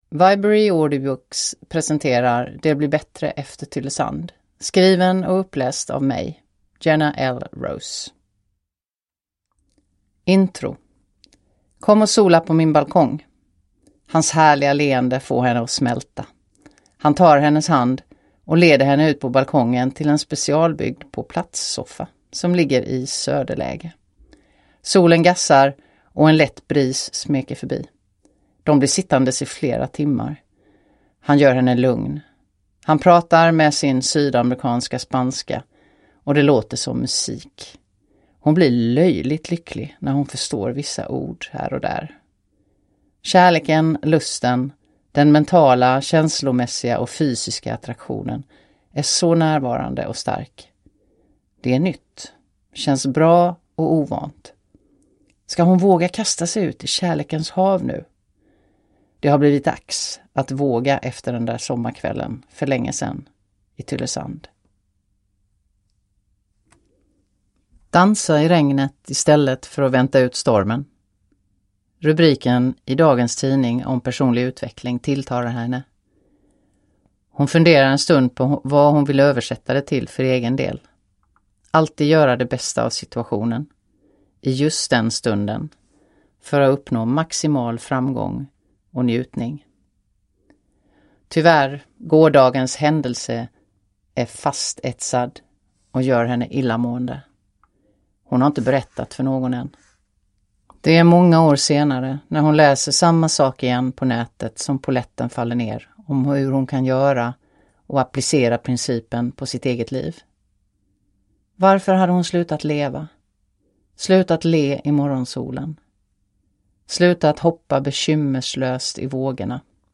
Det blir bättre efter Tylösand – Ljudbok
• Ljudbok